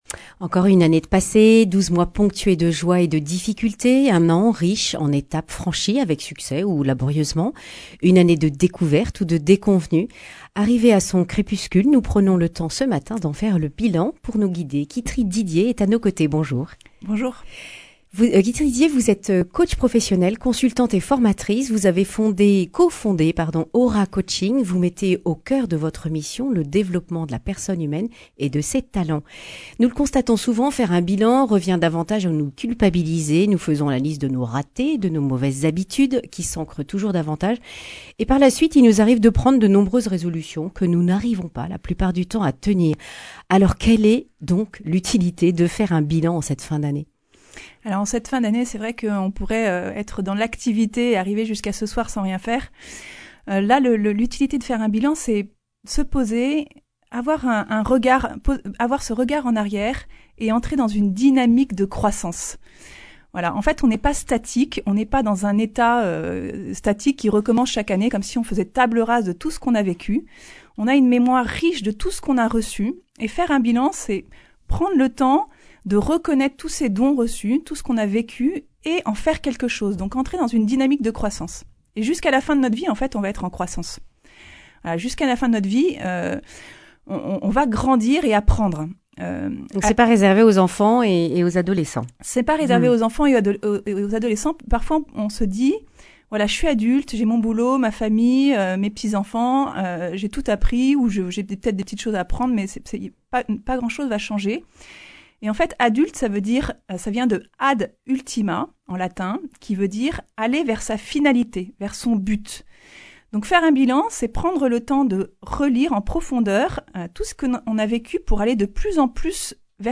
Accueil \ Emissions \ Information \ Régionale \ Le grand entretien \ Pourquoi faire un bilan en cette fin d’année ?